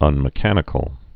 (ŭnmĭ-kănĭ-kəl)